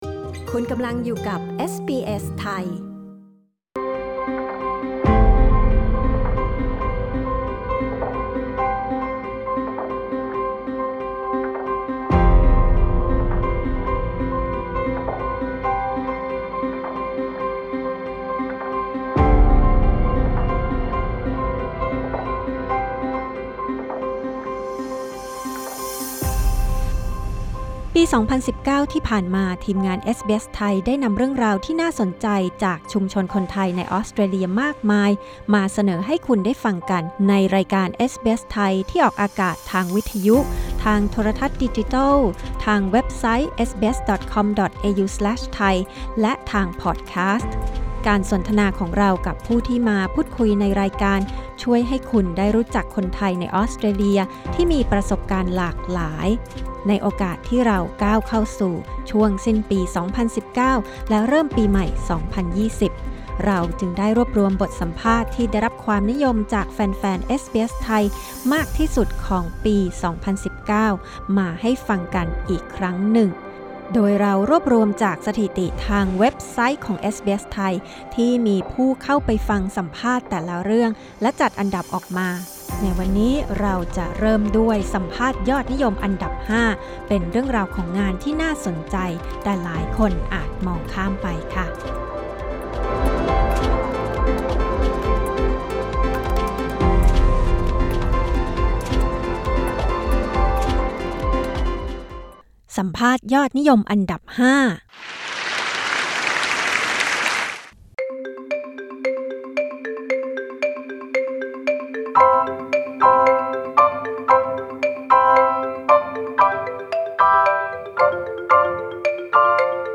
สัมภาษณ์ยอดฮิตอันดับ 5 ของเอสบีเอส ไทย ปี 2019 Source